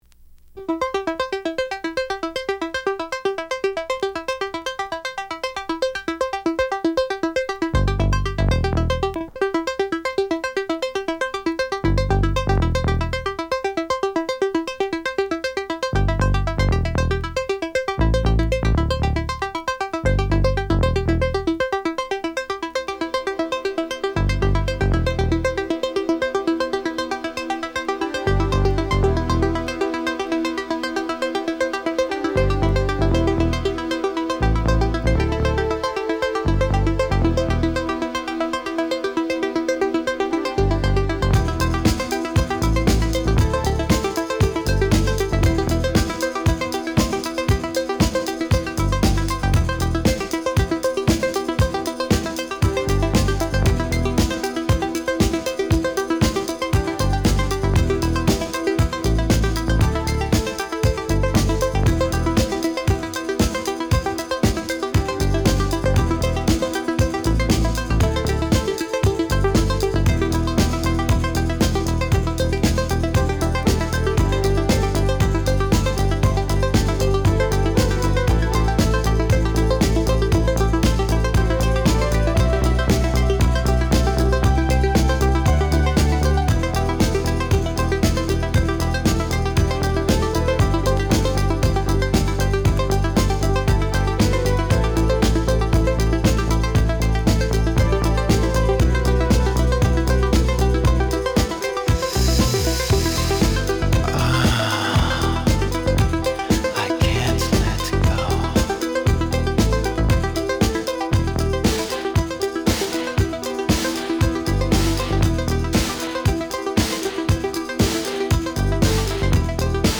Genre: Classic House.